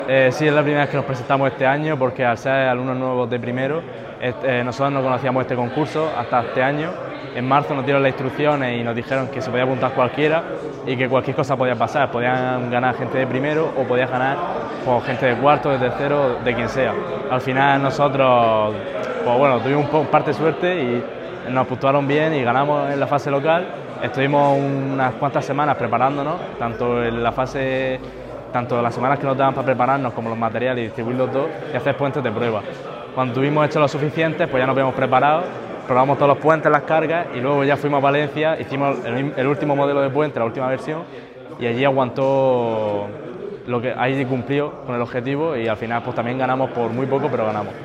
Audio: Declaraciones de la alcaldesa, Noelia Arroyo, visita a los estudiantes UPCT ganadores del Concurso de Puentes (MP3 - 689,36 KB)